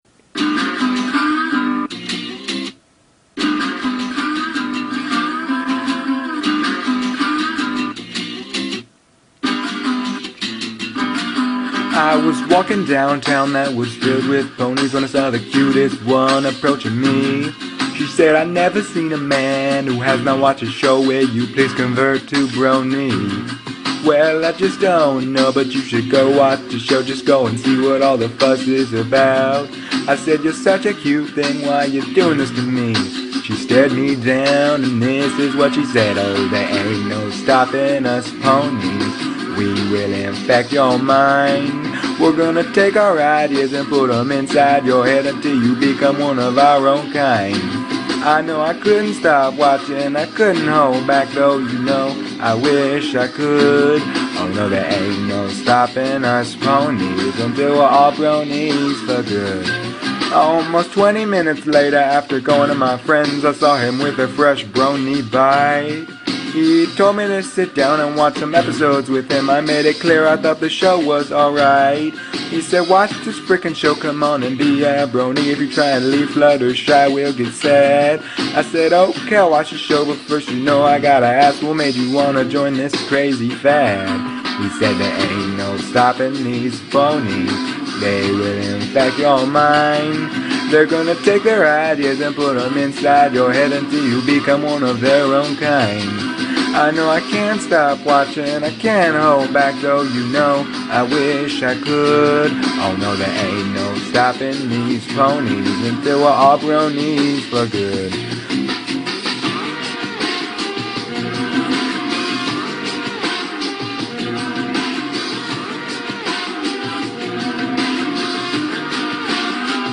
And sorry for my horrible singing.